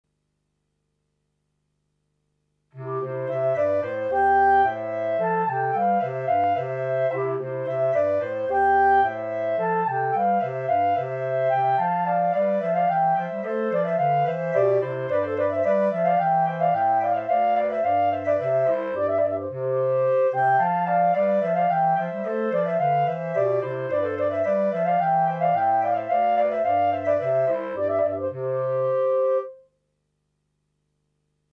Right click to download Hornpipe minus Clarinet 2